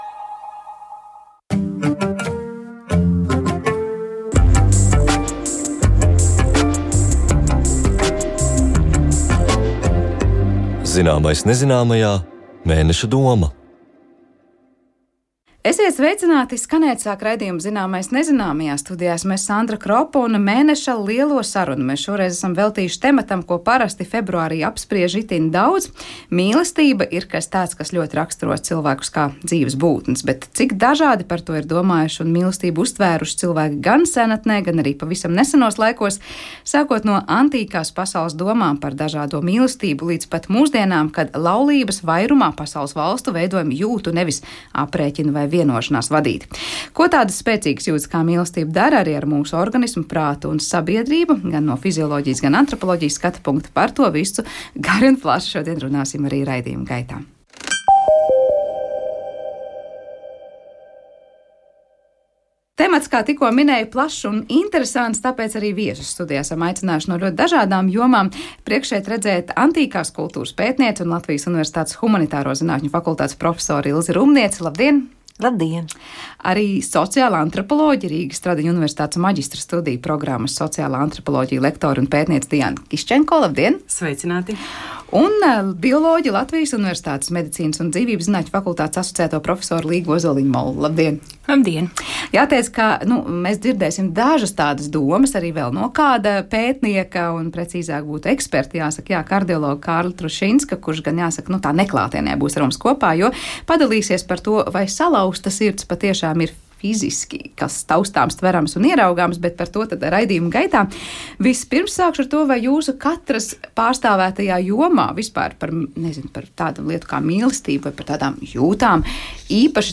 Populārzinātnisks radiožurnāls, kas aptver dažādas zinātnes nozares, izzina dabu, ekoloģiju, ģeogrāfiju, ornitoloģiju, zooloģiju, astronomiju, arheoloģiju, vēsturi, ielūkojas zinātnes un tehnikas sasniegumos, izcilu personību dzīvē un darbībā. Īpaša uzmanība veltīta latviešu zinātnieku sasniegumiem pasaulē un Latvijā. Katrā raidījumā kopā ar pētniekiem iztirzājam „galveno tematu”, uz sadarbību aicinot arī savus klausītājus, vai kā citādi uzklausot cilvēku viedokļus un jautājumus.